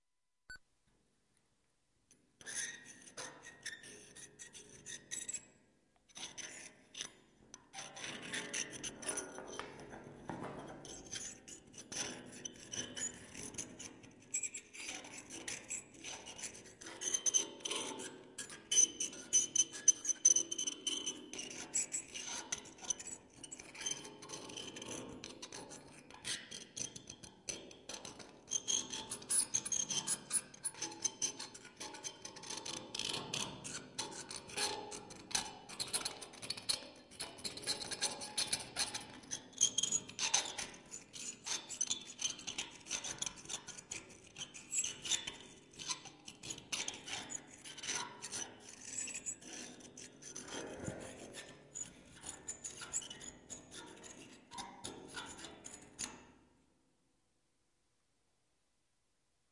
拖动长椅
描述：使用Zoom H4录制并使用Audacity编辑。
Tag: 金属 现场录音 混响